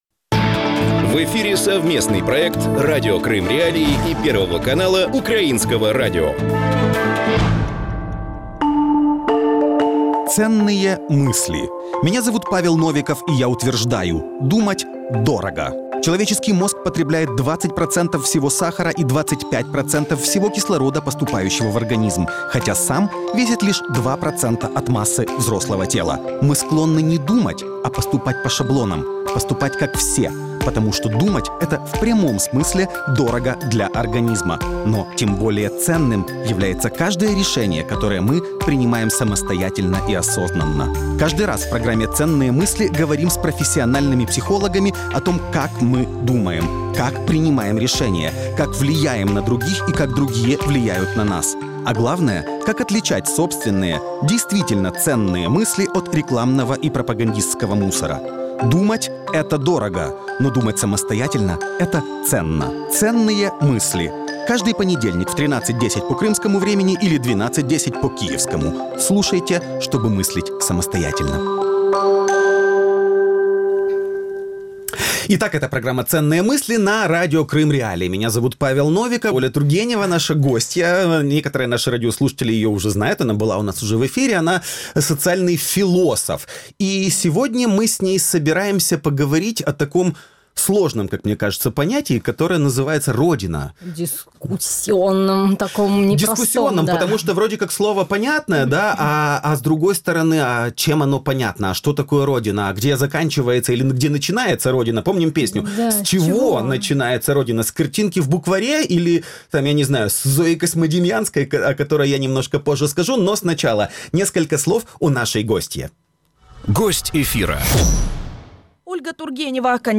С чего начинается Родина? Интервью
Как политика и СМИ влияют на градус патриотизма в обществе? Об этом – в проекте «Дневное шоу» в эфире Радио Крым.Реалии с 12:10 до 12:40.